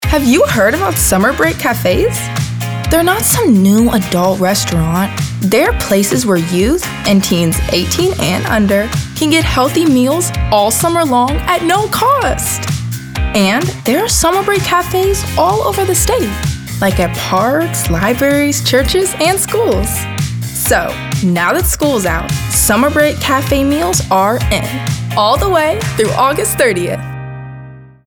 announcer, confident, cool, friendly, genuine, girl-next-door, high-energy, perky, real, teenager, upbeat